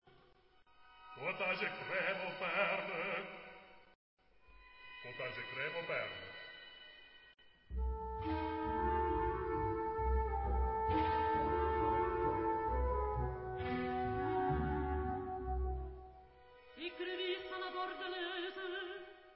Recording: OPERA